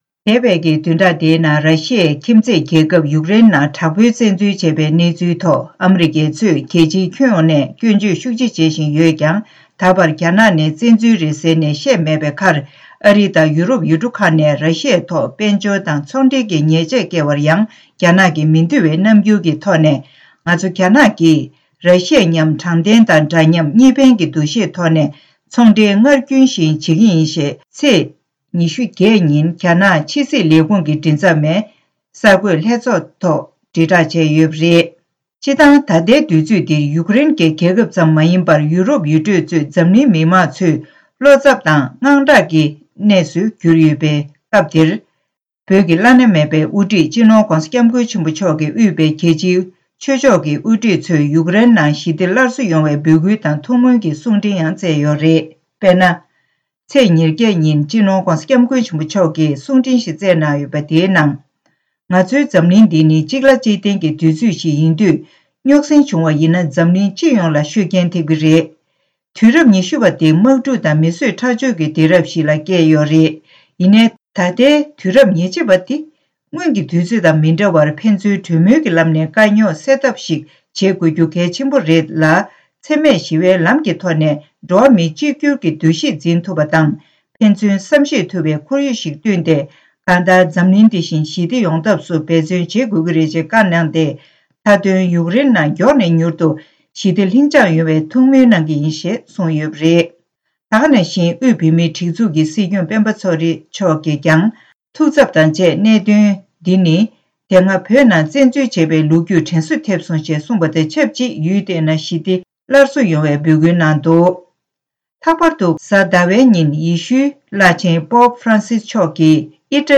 གསར་འགྱུར་དཔྱད་གཏམ་གྱི་ལེ་ཚན་ནང་།